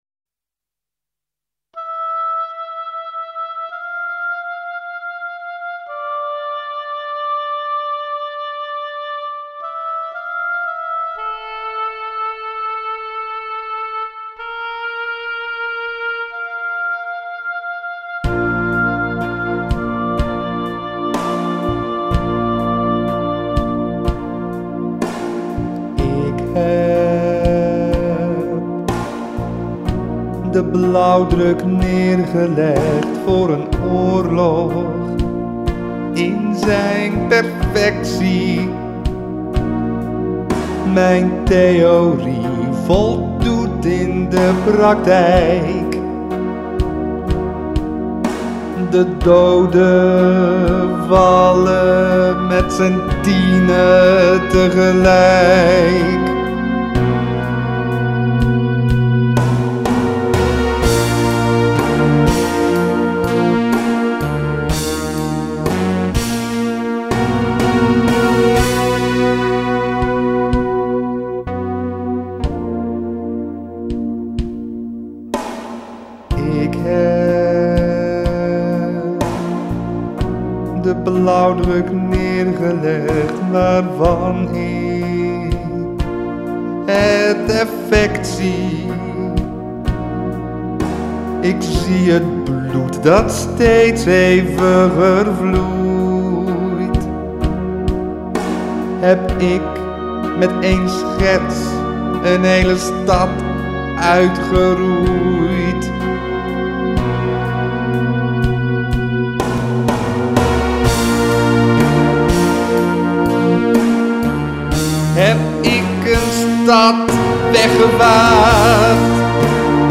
Uit “Da Vinci” (musical)